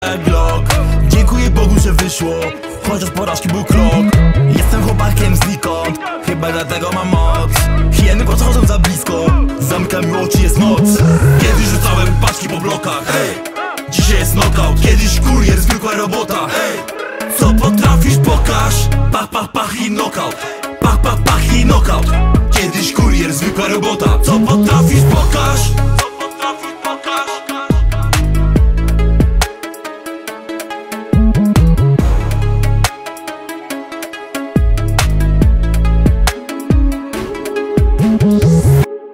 Kategorie Rap